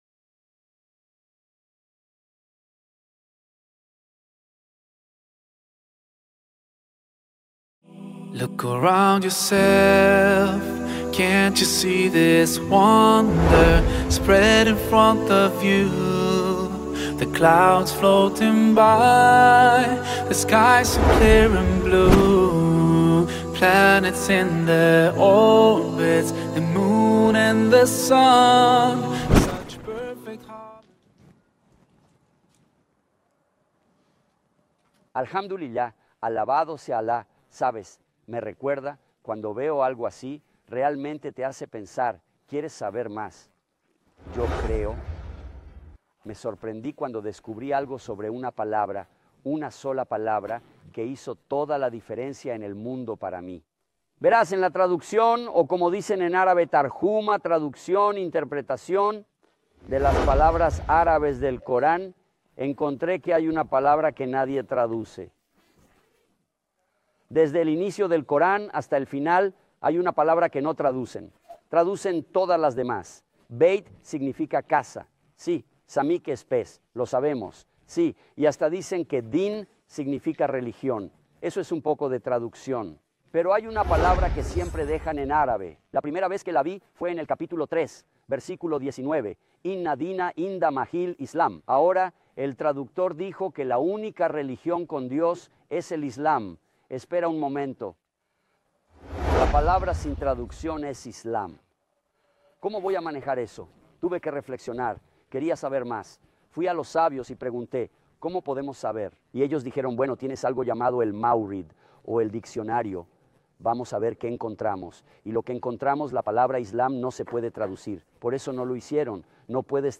filmada en paisajes escénicos y lugares históricos de Jordania. En este episodio, explica lo que significa el Islam.